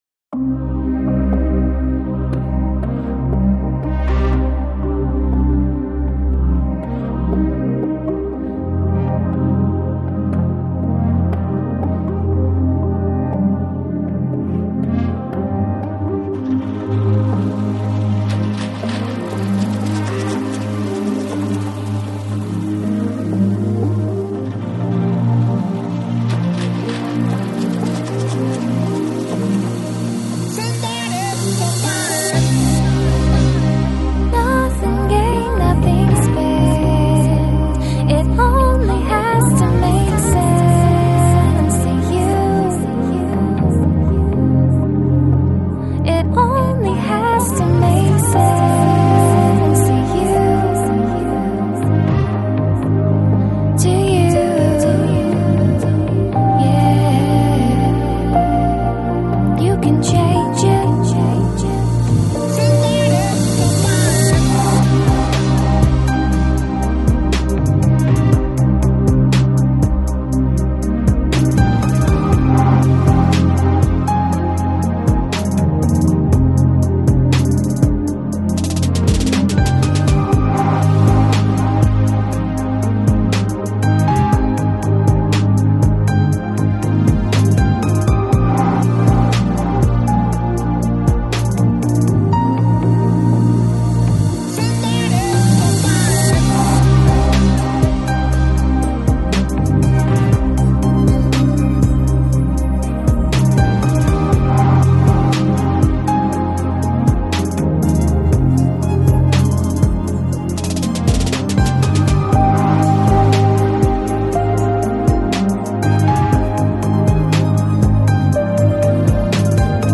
Chill Out, Lounge, Downtempo, Balearic